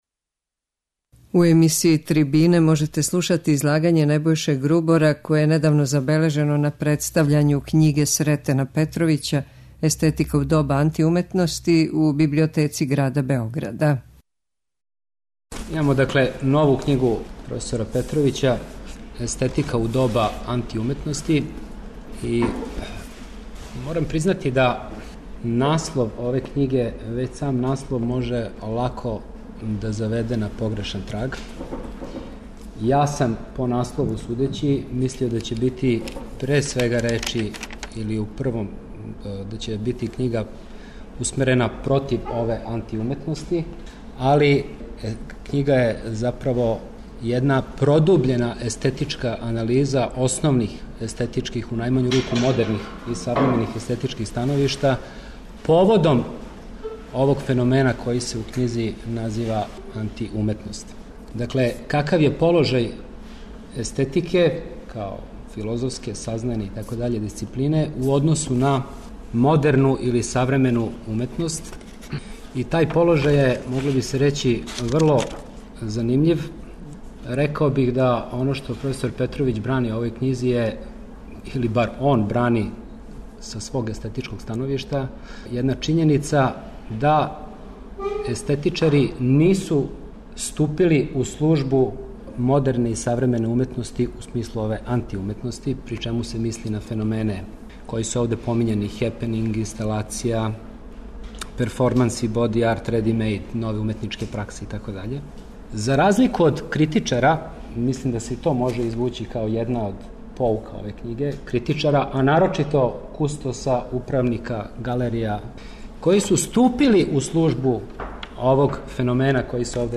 Трибине
преузми : 7.99 MB Трибине и Научни скупови Autor: Редакција Преносимо излагања са научних конференција и трибина.